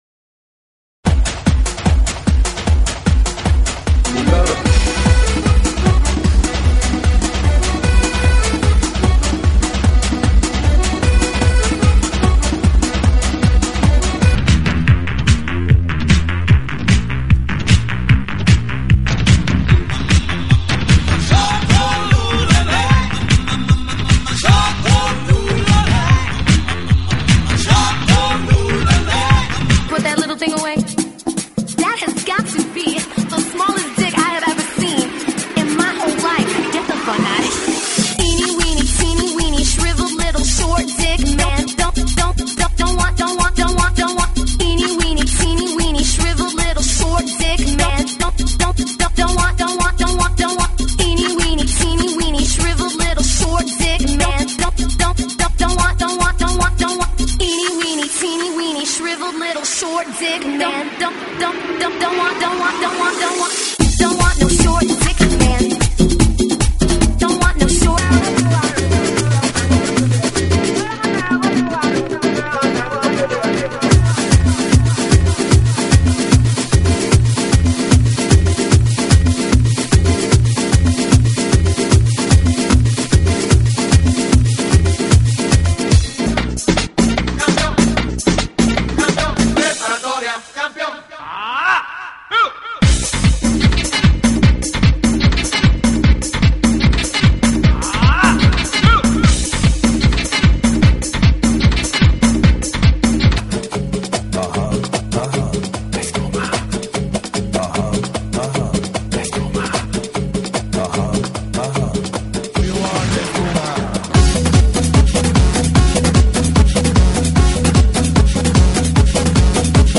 GENERO: LATINO – TRIBAL
TRIBAL LATINO,